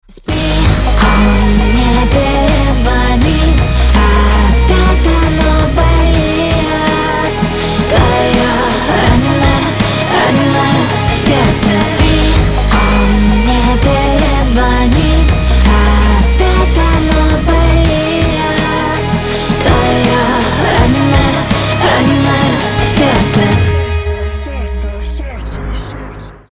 • Качество: 320, Stereo
грустные
красивый женский голос